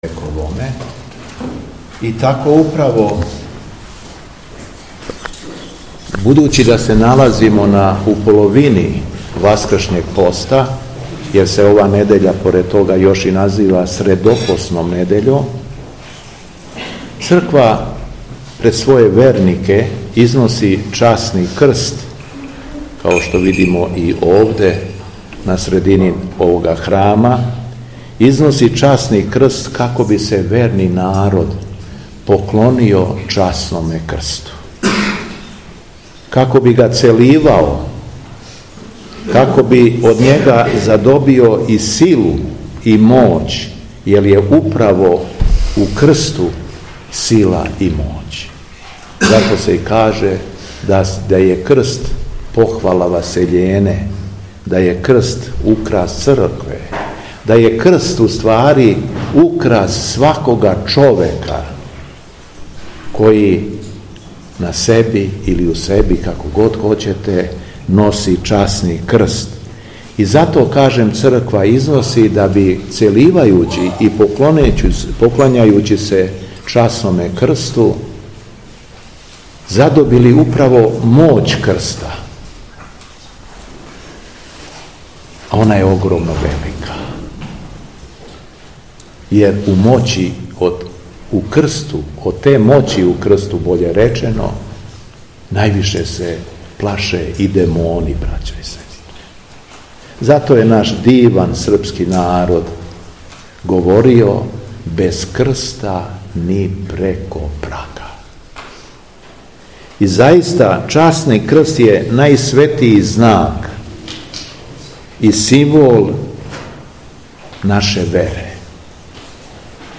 Беседа Његовог Високопреосвештенства Митрополита шумадијског г. Јована
У недељу Крстопоклону, трећу недељу Великог поста, 23. марта 2025. године, Његово Високопреосвештенство Архиепископ крагујевачки и Митрополит шумадијски Господин Јован служио је Свету Литургију у манастиру Светог великомученика Димитрија у селу Бајчетина, надомак Крагујевца.